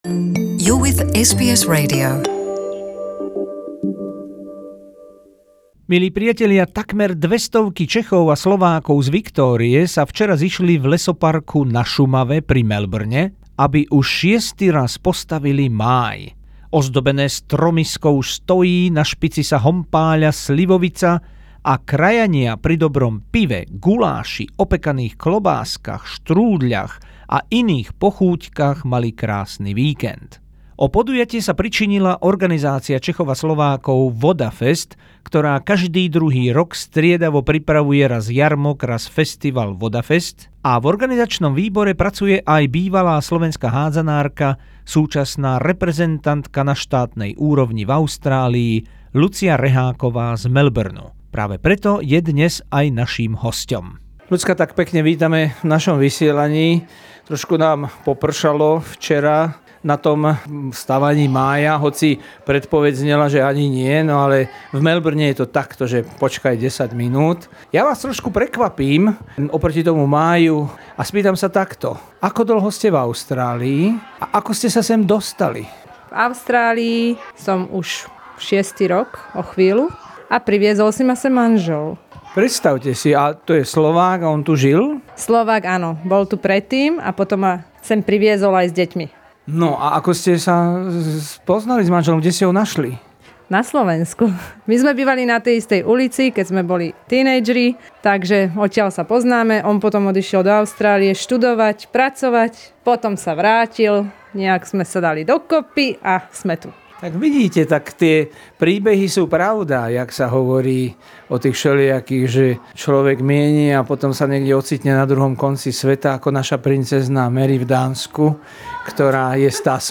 Natočené na obľúbenom stavaní Mája vďaka Vodafestu na Šumave pri Melbourne 2018